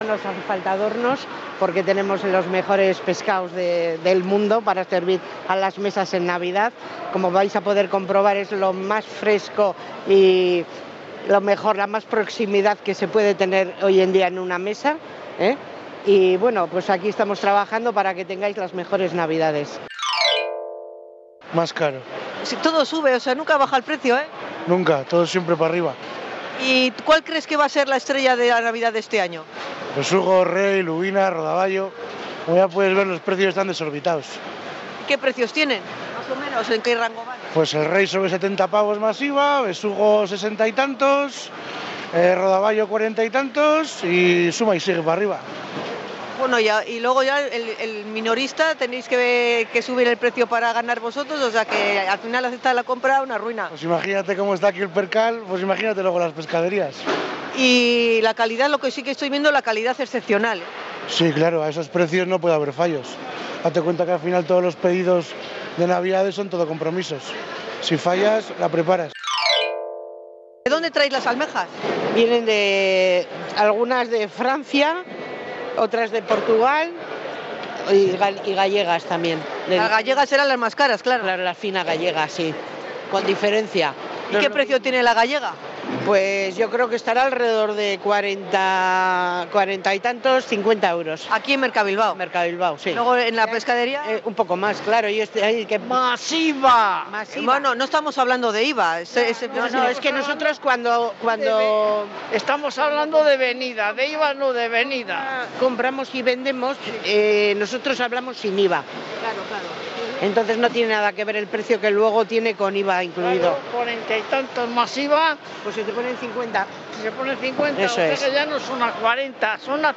Crónica MercaBilbao
Kontxi Claver Zurro, concejala de Desarrollo Económico, Comercia, Turismo y Empleo, comenta la situación de esta mañana en Mercabilbao.